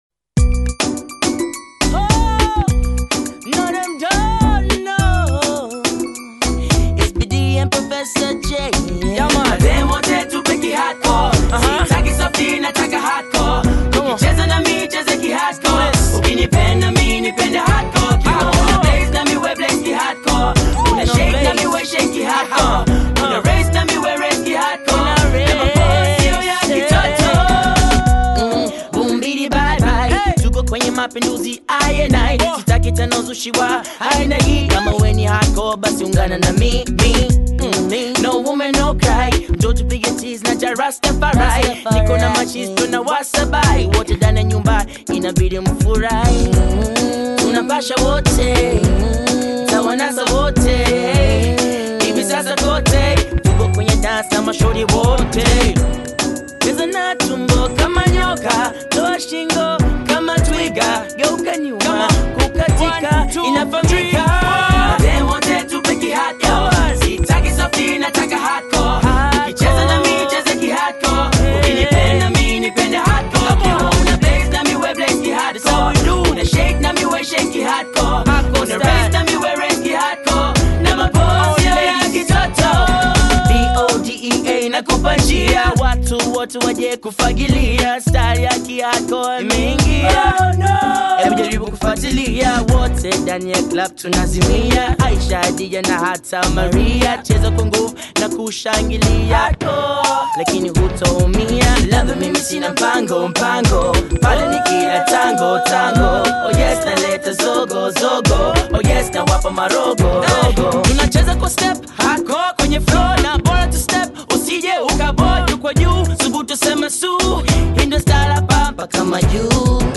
” a dynamic fusion of gritty beats and powerful lyrics.